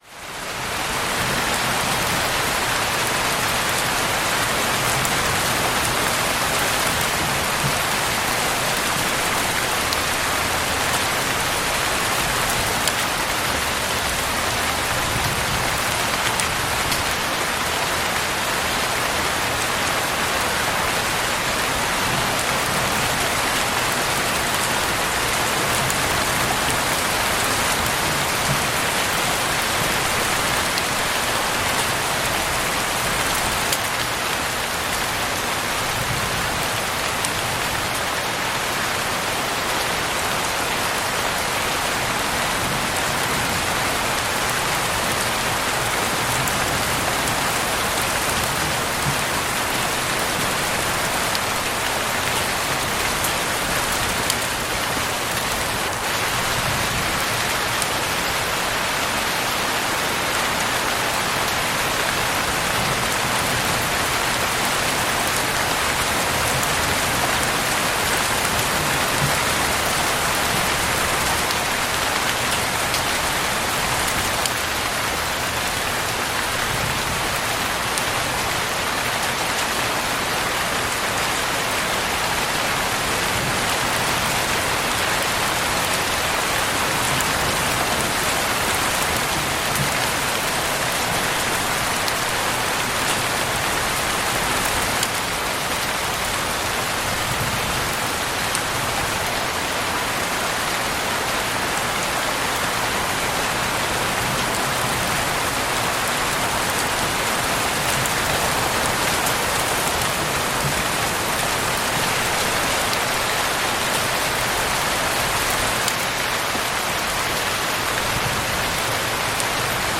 Ambiance de Pluie sur Tente pour un Sommeil Profond et un Esprit Calme